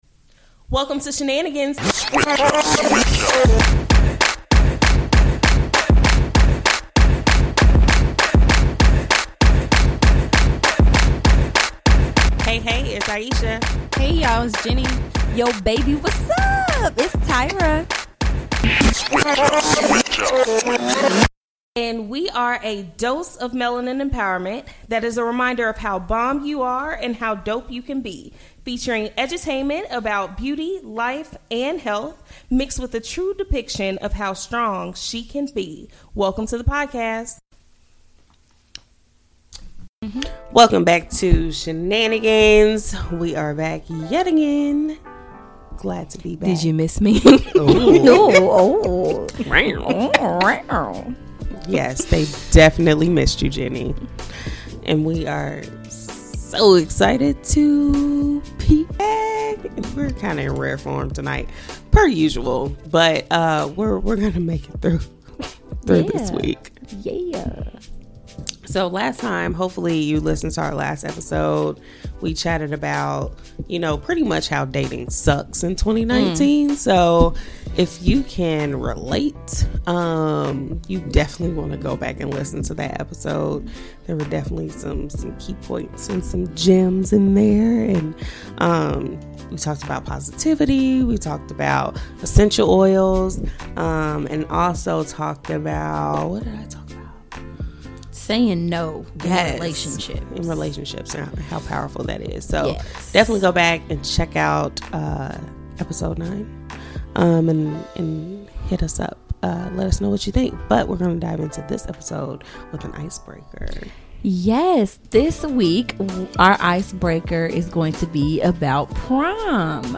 They laugh about prom memories for the icebreaker. They also share tips on how to boost your energy, invest in yourself and balancing your goals while in a relationship.